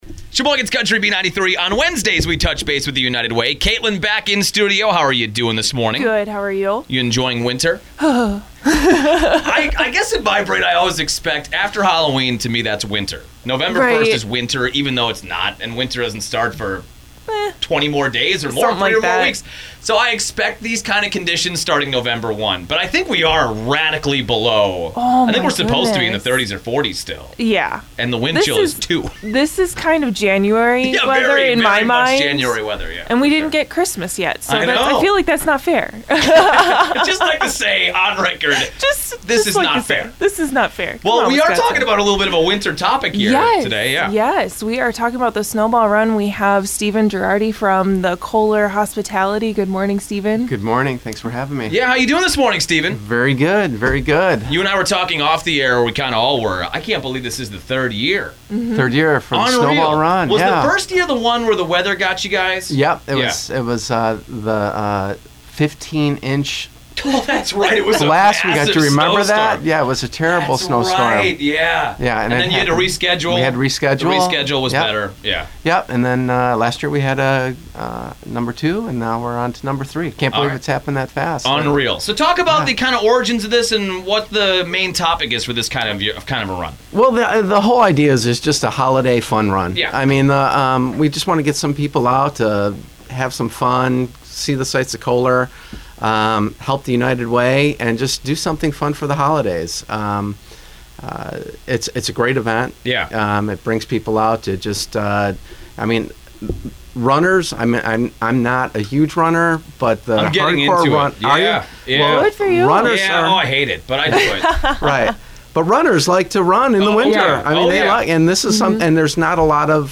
Radio Spot 11-28-18 Snowball Run